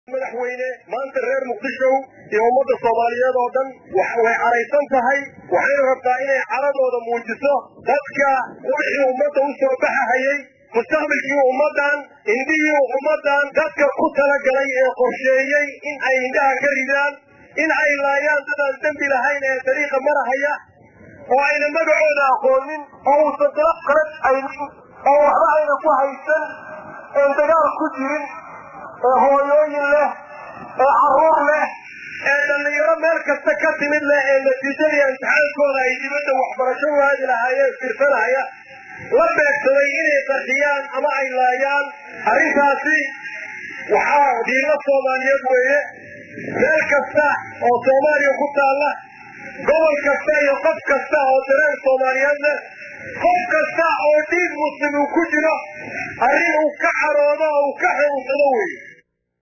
Banaabax loogu magac daray maalinta cadhada ayaa lagu qabtay Garoonka burburay ee Koonis Stadium ee magaalada Muqdisho.